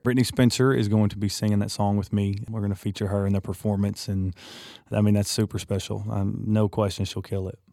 Parker McCollum talks about having special guest Brittney Spencer perform with him on the CMT Music Awards.